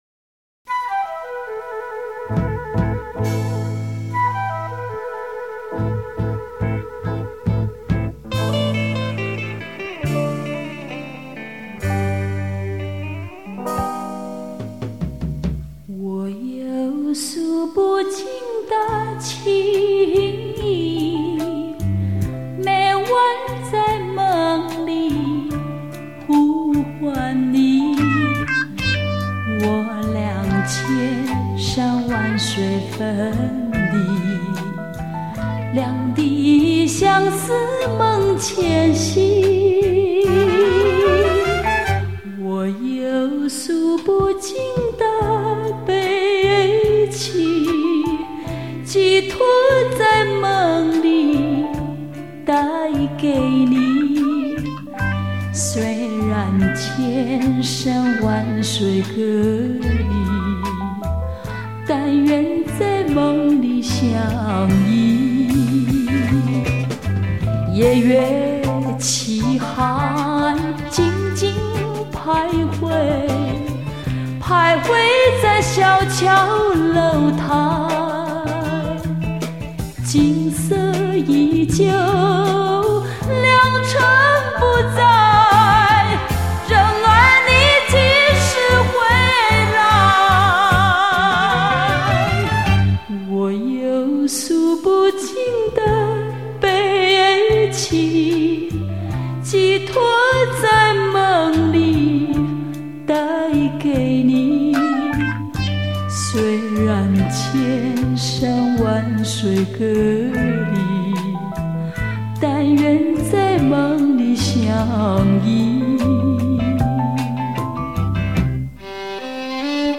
本套全部歌曲皆数码系统重新编制 令音场透明度及层次感大为增加
并使杂讯降为最低 在任何音响组合中均可发挥最完美音色